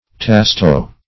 Search Result for " tasto" : The Collaborative International Dictionary of English v.0.48: Tasto \Tas"to\ (t[aum]s"t[-o]), n. [It.]